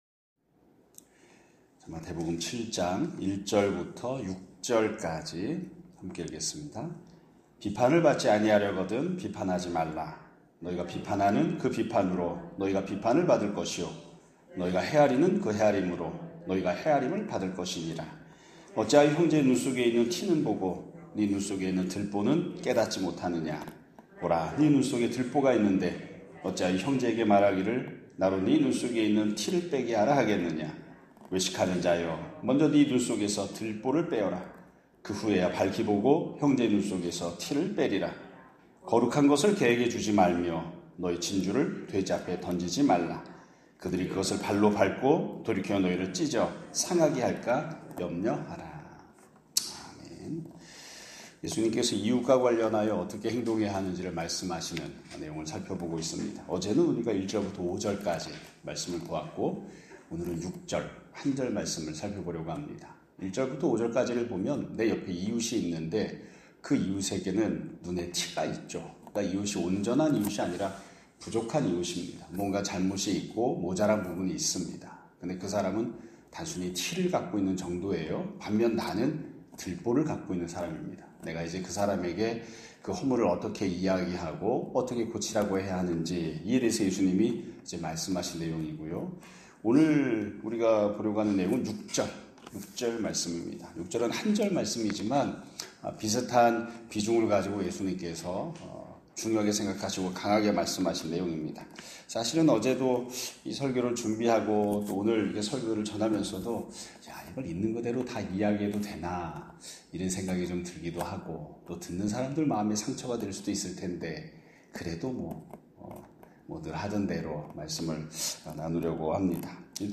2025년 6월 24일(화요일) <아침예배> 설교입니다.